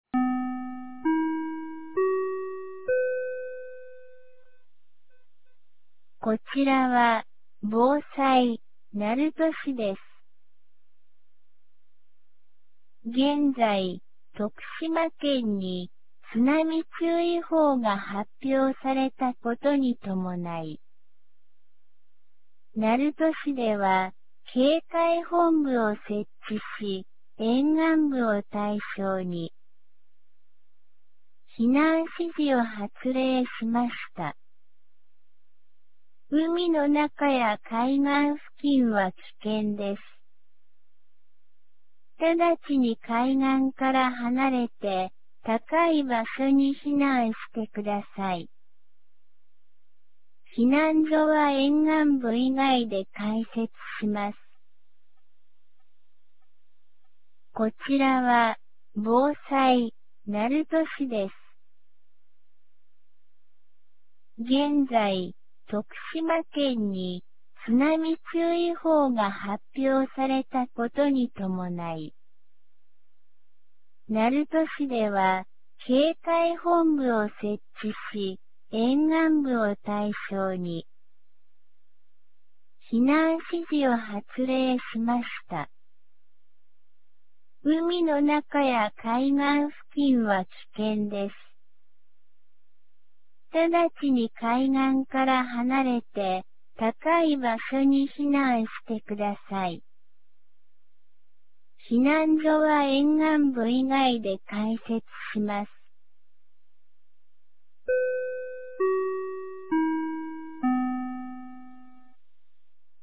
2025年07月30日 11時03分に、鳴門市より全地区へ放送がありました。